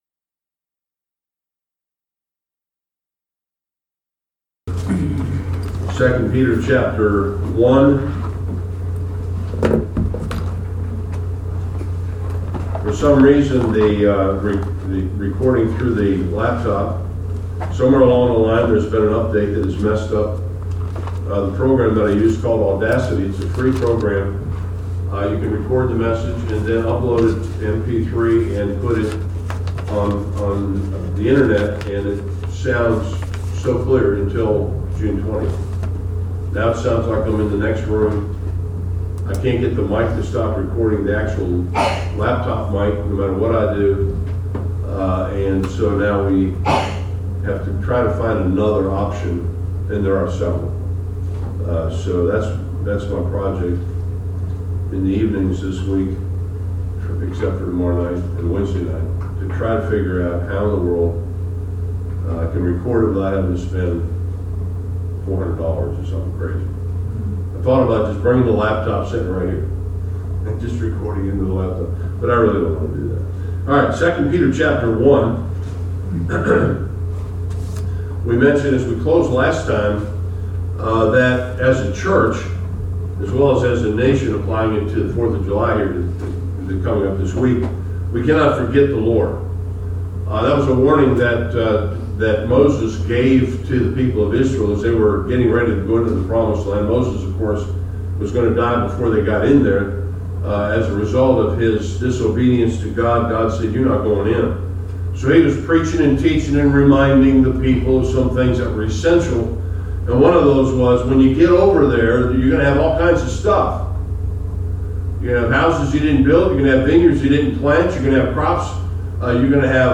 Service Type: Sunday School